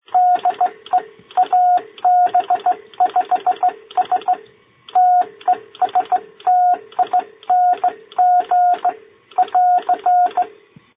Attached is an .mp3 of BC-230, crystal controlled on 10.110 KC (5055 crystal, double in the PA.)